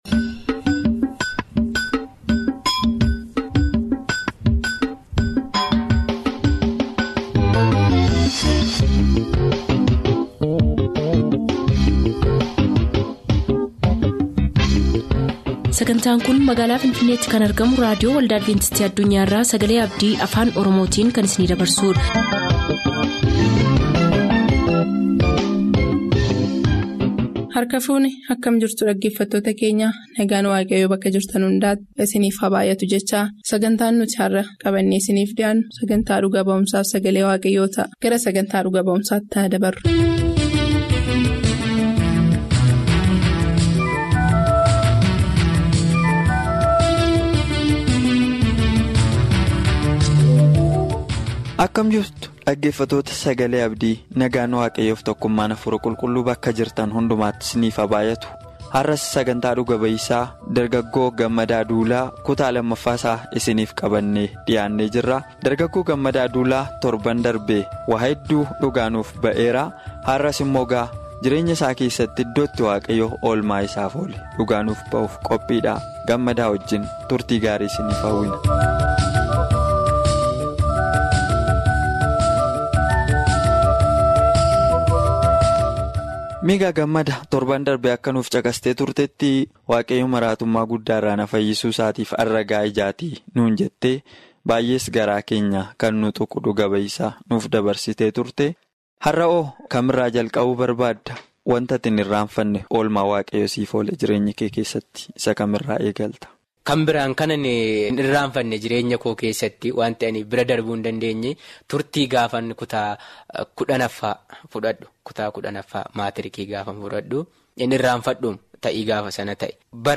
MUUXANNOO JIREENYYAA FI LALLABA. WITTNESING AND TODAY’S SERMON